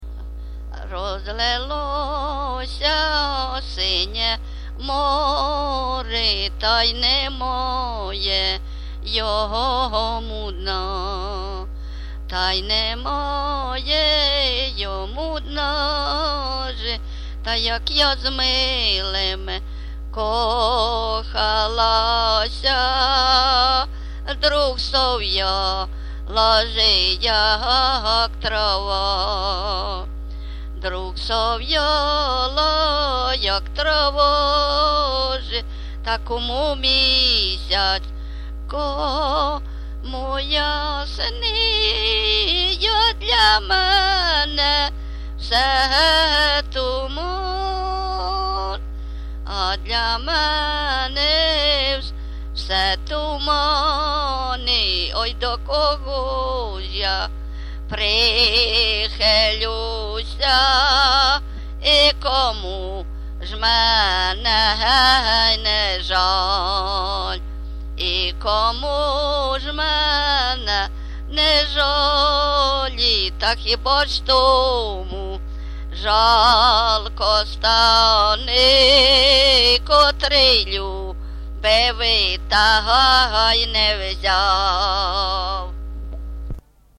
ЖанрПісні з особистого та родинного життя
Місце записус. Нижні Рівні, Чутівський район, Полтавська обл., Україна, Слобожанщина